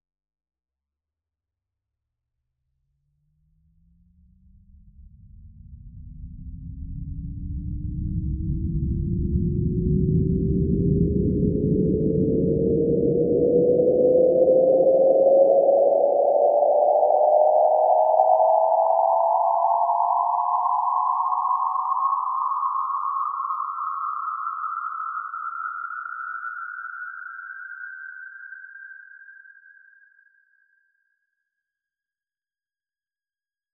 Thirteen studies in soundscape-style, based op atomic spectra.
In this nanotonal music, frequencies are played very accurately (63 bit) by computer. Rythms arise - as a matter of course - by the beatings between the many spectral lines that sound together.
These are all flac files, 16 bit, stereo, 44.1 KHz.
The most complex and dense spectra come from iron and copper, by accident exactly those 2 that respectively symbolize the masculine and the feminine.
The titles of the glissando-études (ST0.33xx) are deceptive because pitch-rise only occurs in discrete steps (quantum-leaps), so actually these are tone-scales. Remarkeble is the "cadence-quality" of these rising scales.